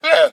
minecraft / sounds / mob / llama / idle4.ogg